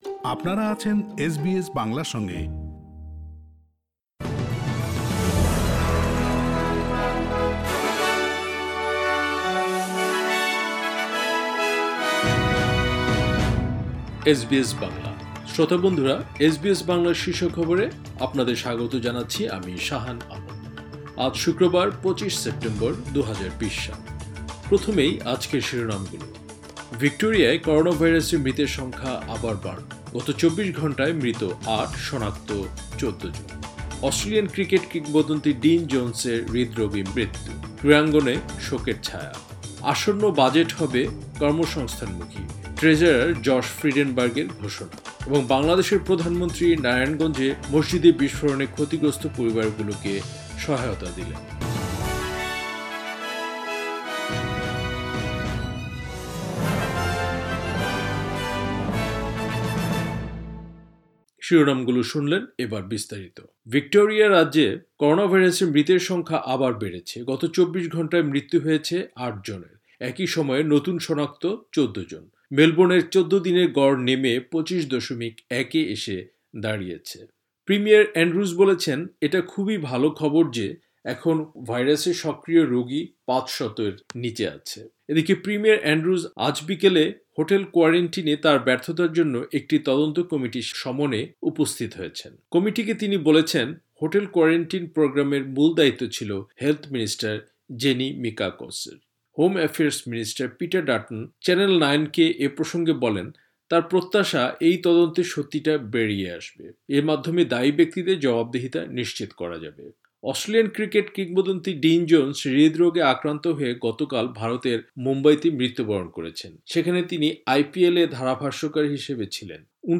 এসবিএস বাংলা শীর্ষ খবর, ২৫ সেপ্টেম্বর, ২০২০